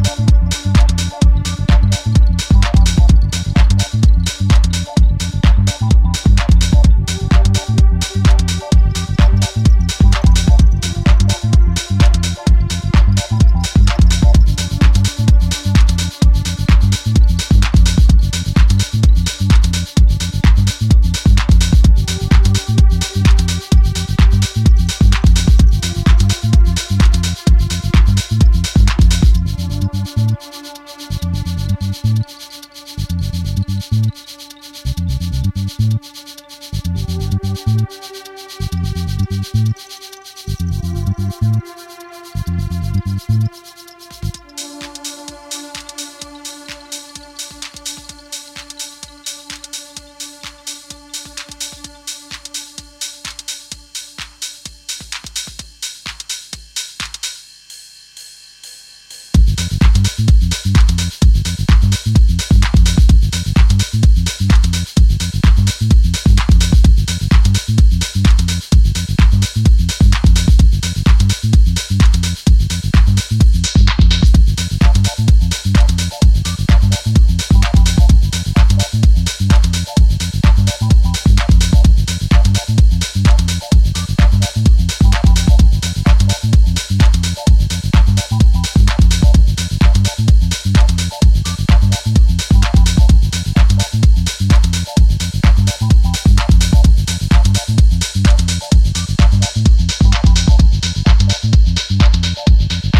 スモーキーなフィルターベースがファンキー、地味ながら確実にフロアに釘付けにされる逸品。